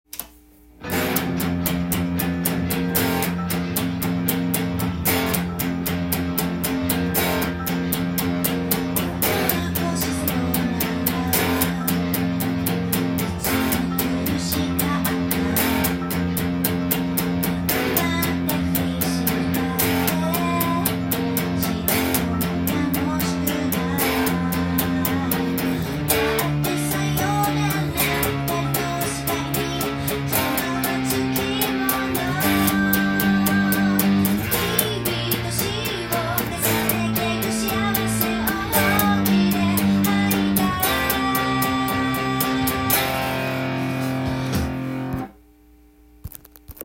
音源にあわせて譜面通り弾いてみました
危険な香りがする曲です。
最初のコードがF♭５
あえて使うことでヤバい雰囲気を醸し出しているようです。
tab譜では簡単に弾けるように低音弦をブリッジミュート